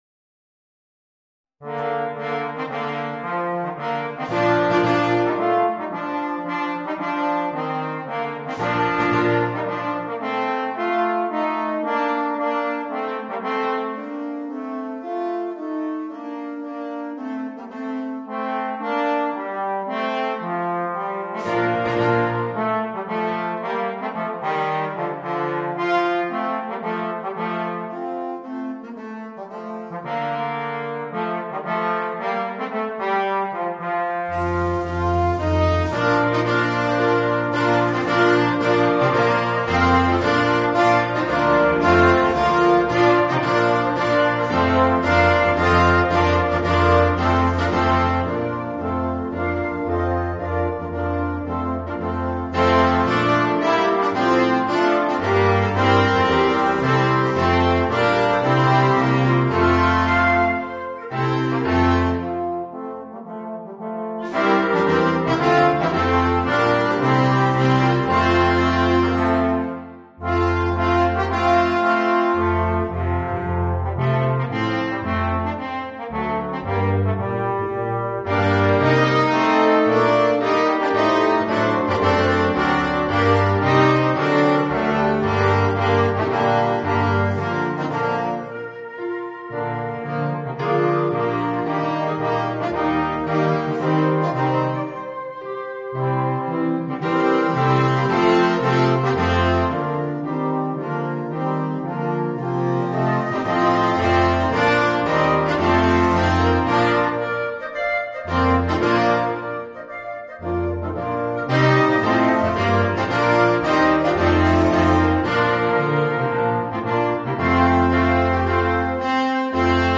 Voicing: 8 - Part Ensemble